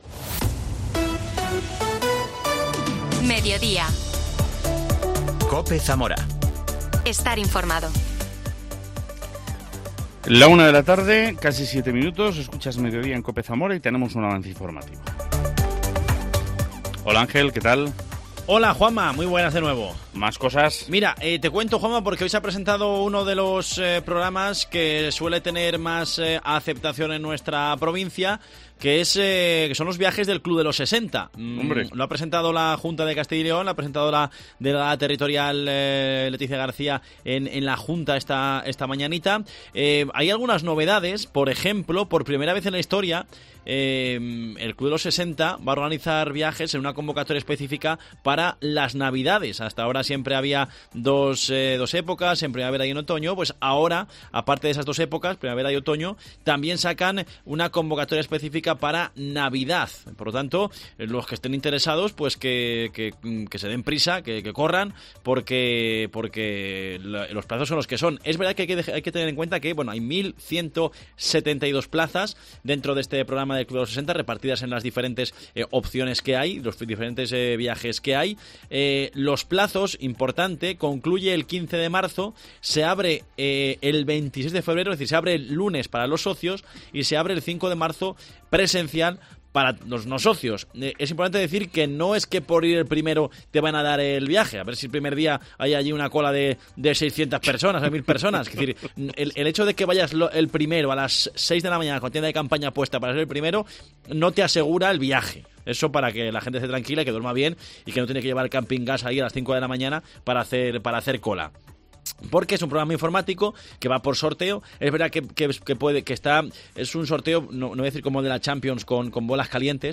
AUDIO: La viceconsejera de Acción Cultural, Mar Sancho, habla sobre el juego Los pequeños gigantes de la lectura, en el que una niña zamorana y un...